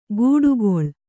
♪ gūḍugoḷ